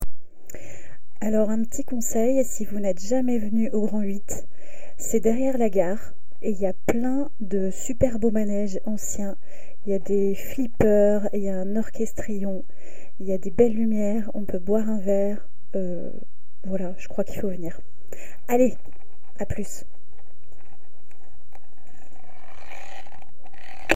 Cabine de témoignages
Témoignage du 26 septembre 2025 à 13h32